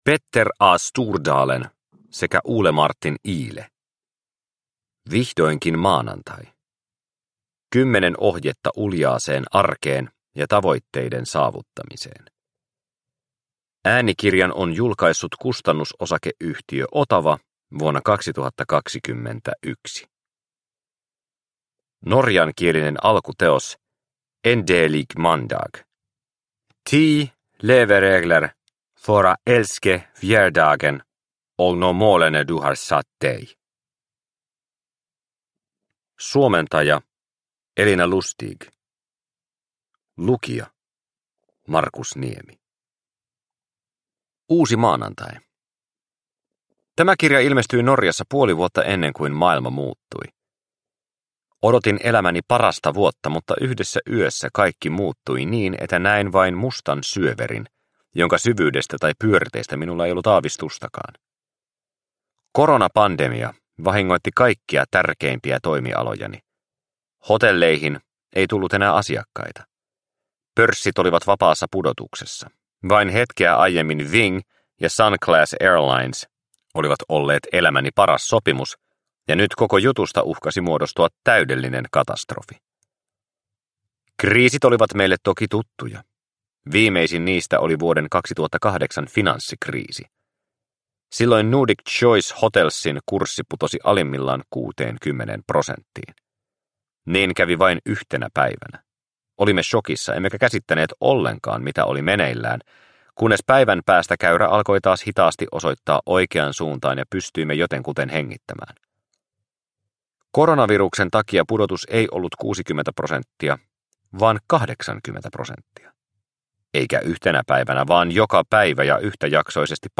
Vihdoinkin maanantai! – Ljudbok – Laddas ner